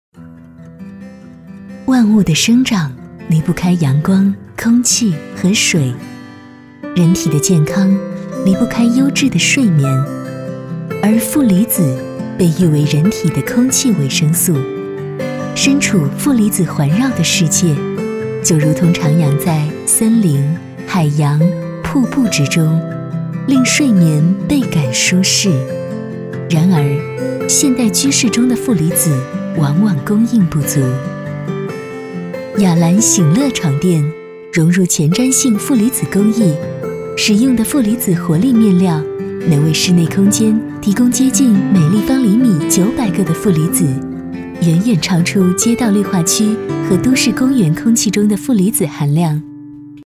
女66-广告 雅兰床垫 温柔
女66大气专题 v66
女66-广告--雅兰床垫-温柔.mp3